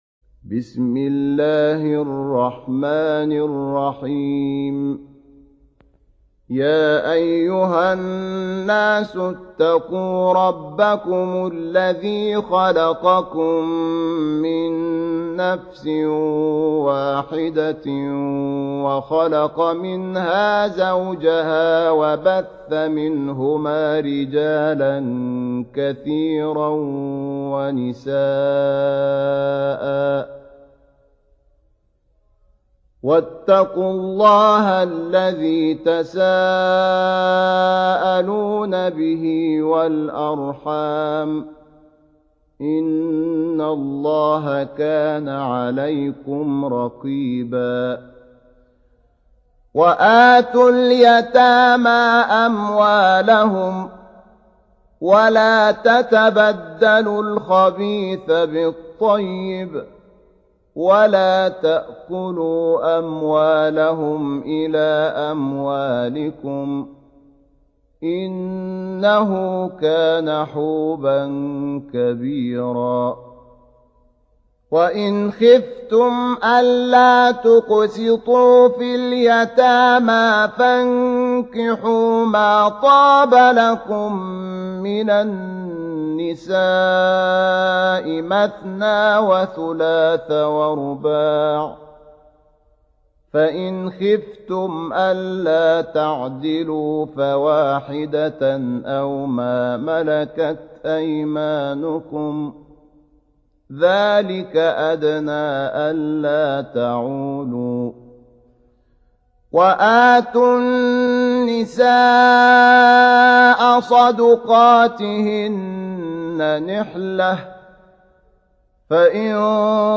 4. Surah An-Nis�' سورة النساء Audio Quran Tarteel Recitation
الشيخ شحات محمد انور | حفص عن عاصم Hafs for Assem
Surah Repeating تكرار السورة Download Surah حمّل السورة Reciting Murattalah Audio for 4.